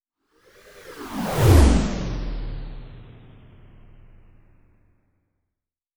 Swoosh Transition Sound Effect Free Download
Swoosh Transition